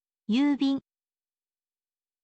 yuubin